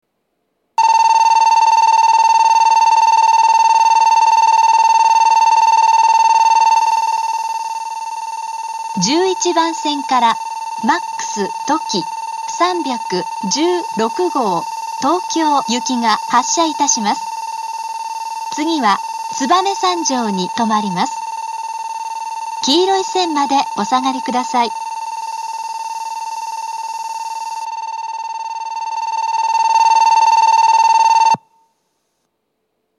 ２面４線のホームで、全ホームで同じ発車ベルが流れます。
１１番線発車ベル Ｍａｘとき３１６号東京行の放送です。